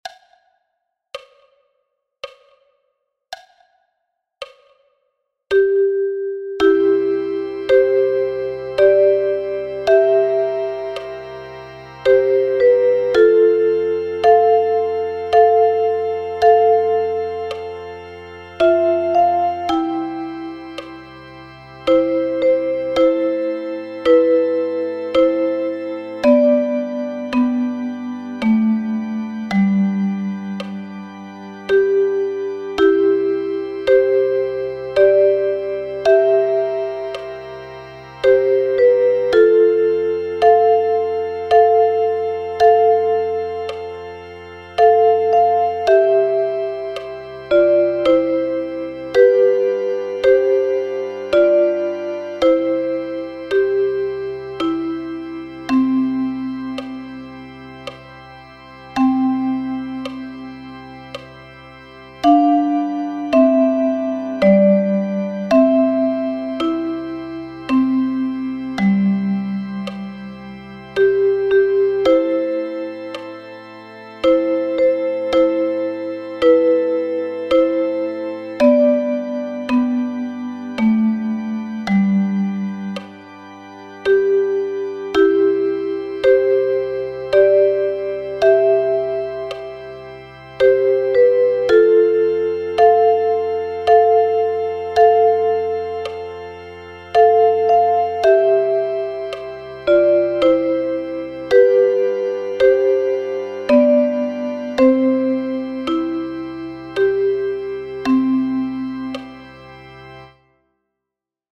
notiert als Duette für Sopranblockflöte und Altblockflöte.